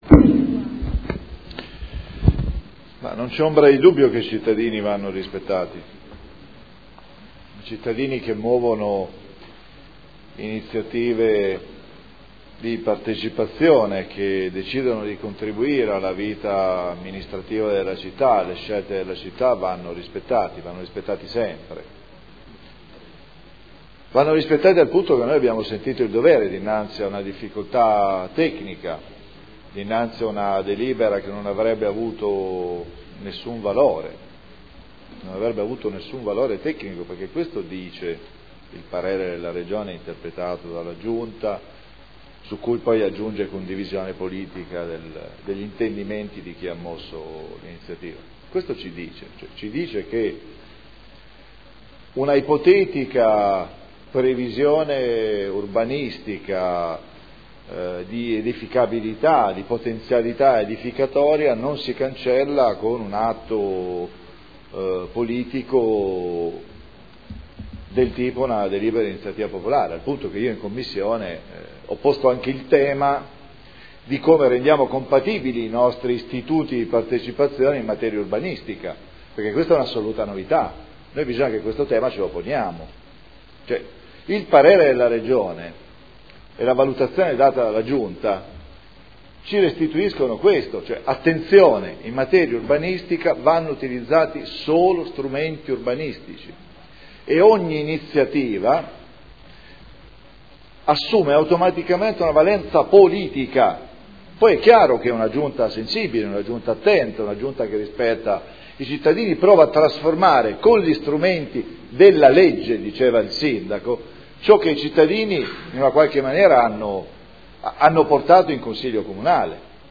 Seduta del 7 aprile.
Dibattito